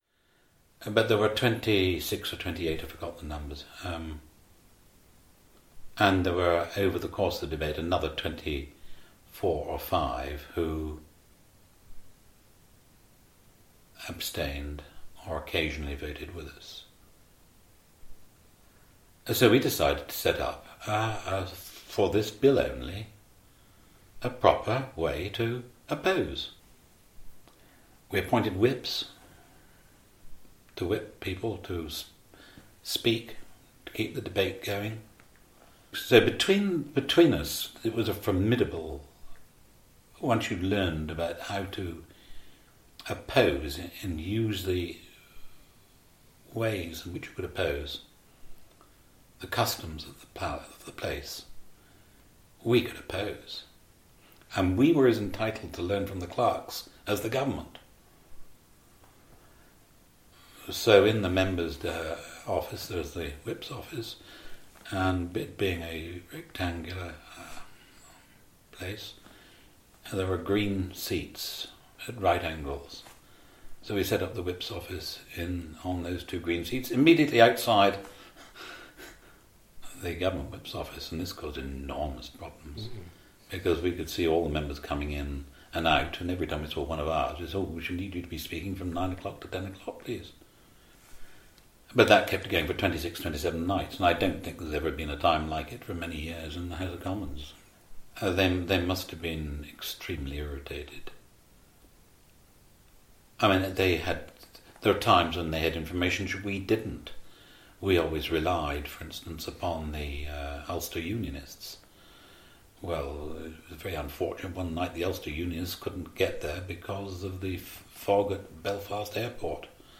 20th century history, Contemporary History, Diplomacy and International Relations, Factions, John Major, Modern, Oral history, Parties
The tactics worked on enough Conservative MPs to pass the legislation, after some close shaves, but not after an uphill battle, as described here by rebel  and MP for Stroud Roger Knapman: